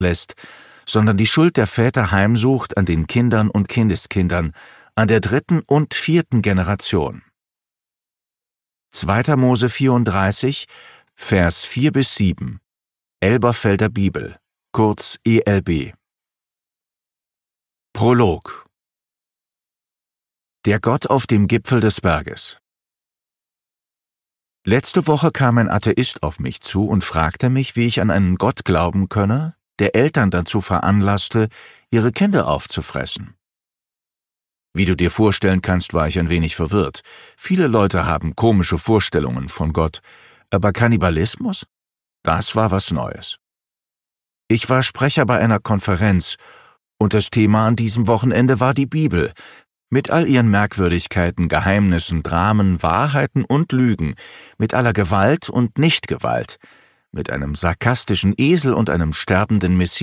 Gott hat einen Namen - Hörbuch